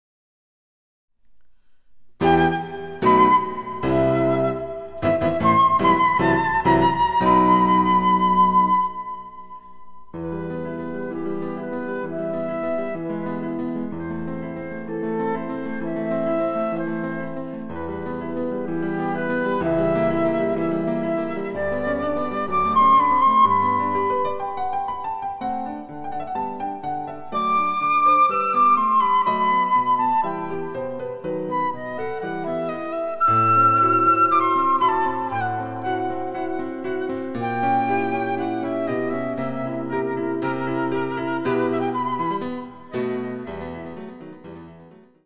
★フルート用の名曲をピアノ伴奏つきで演奏できる、「ピアノ伴奏ＣＤつき楽譜」です。